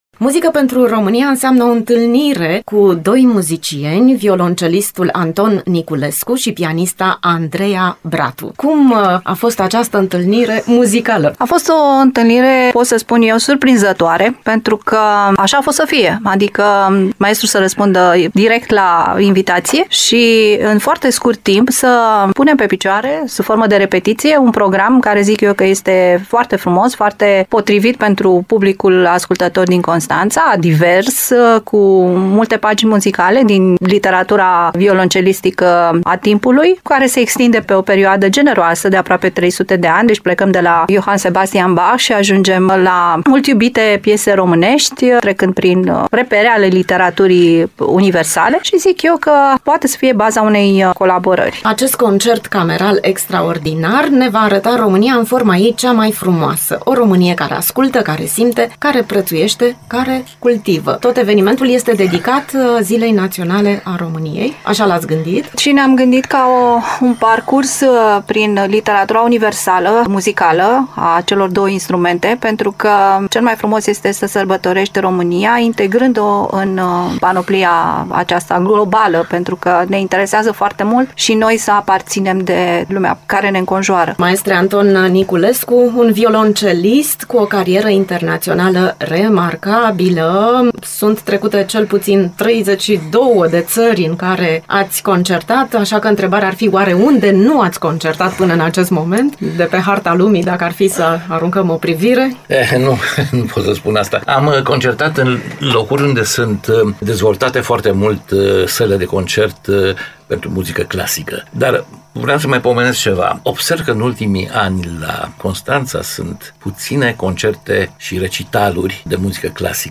În interviul acordat în exclusivitate